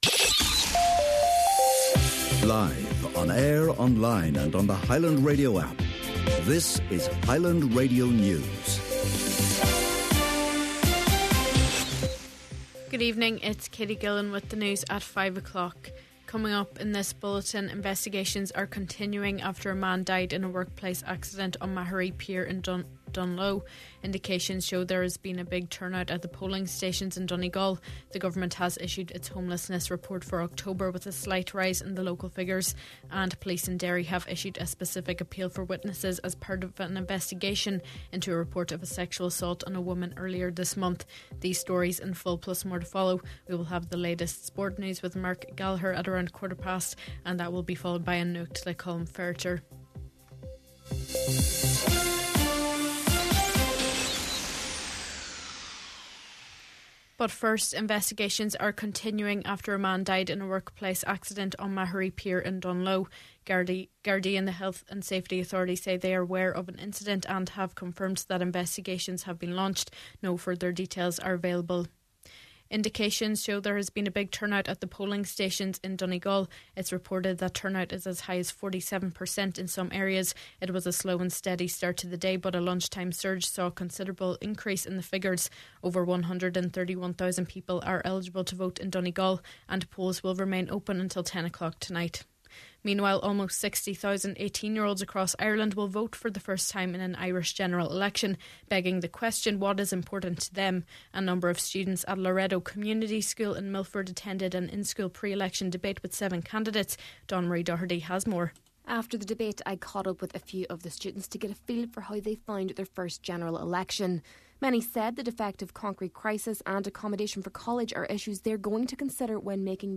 Main Evening News, Sport, An Nuacht and Obituaries – Friday, November 29th